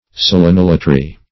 selenolatry - definition of selenolatry - synonyms, pronunciation, spelling from Free Dictionary